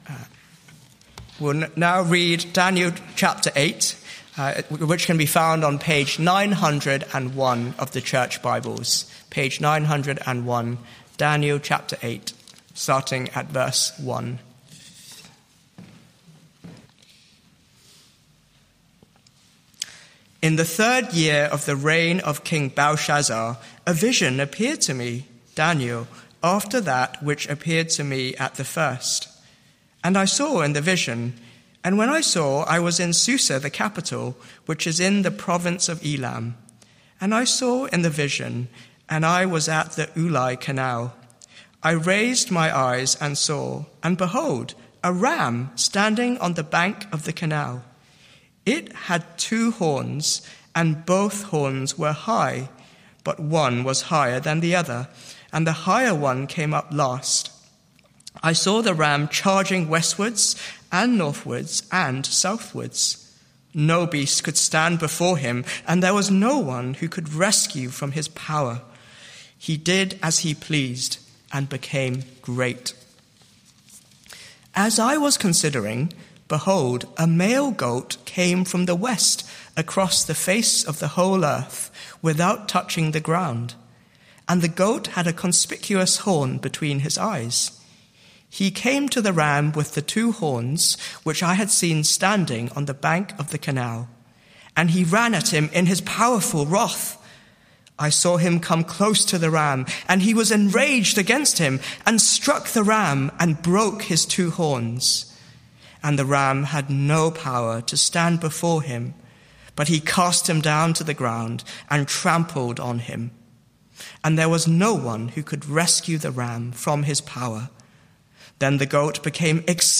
Media for Evening Meeting on Sun 06th Jul 2025 18:00 Speaker
Sermon Search media library...